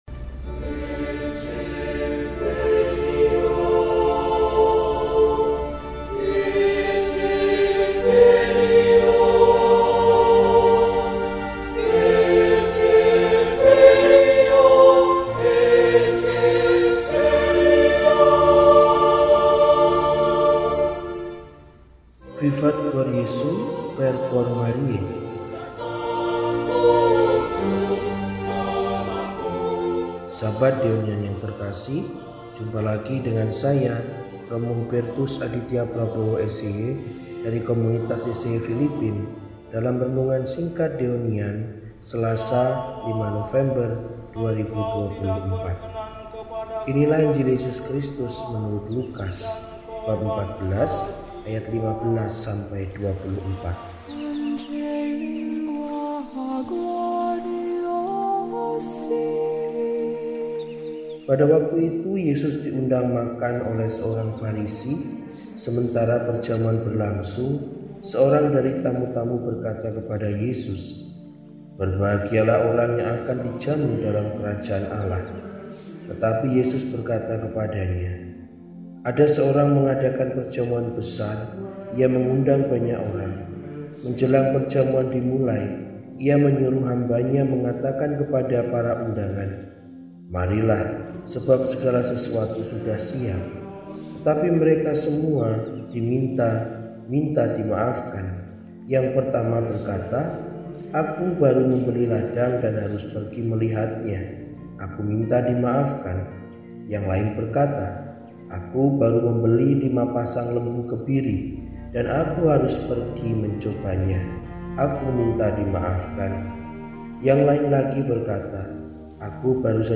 Selasa, 05 November 2024 – Hari Biasa Pekan XXXI – RESI (Renungan Singkat) DEHONIAN